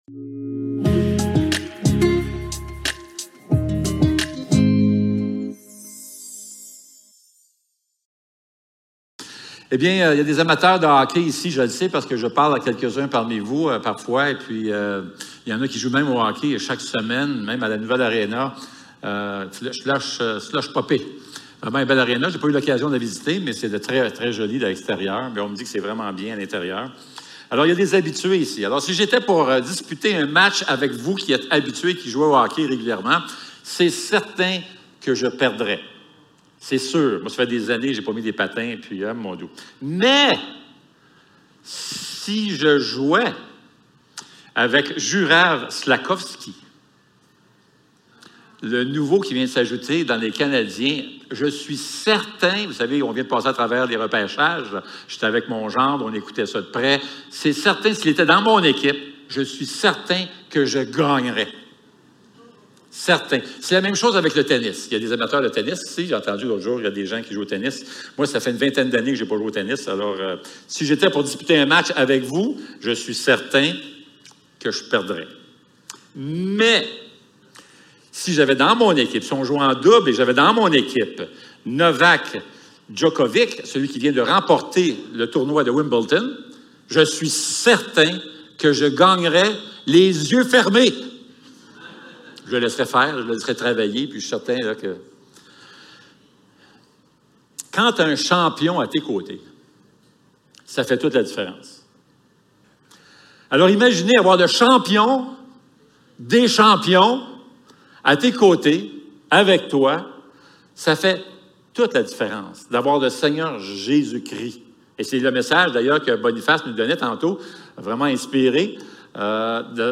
Actes 23 Service Type: Célébration dimanche matin Envoyés #35 Il arrive qu'on prie